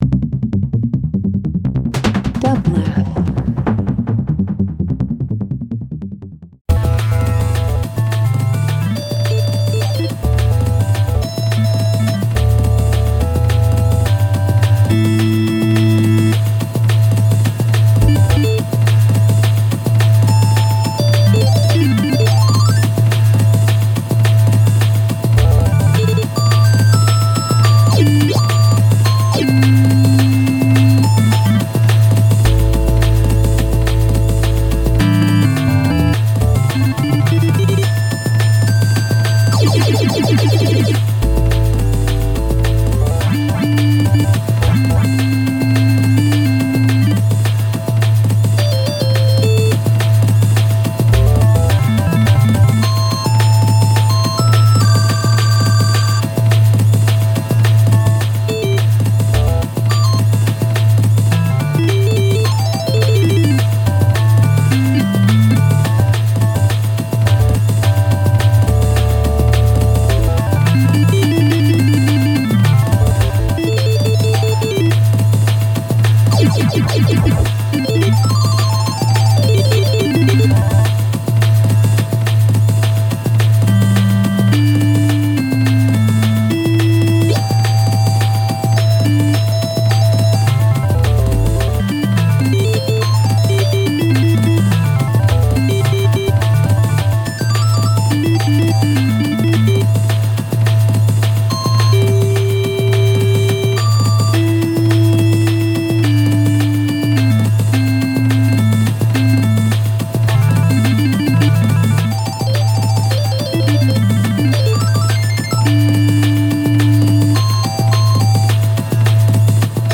Alternative Classical Indian International